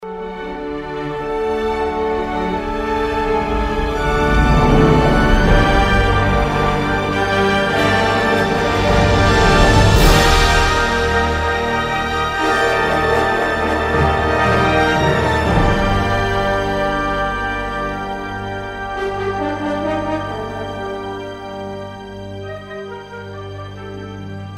en su variante sinfónica-heroica…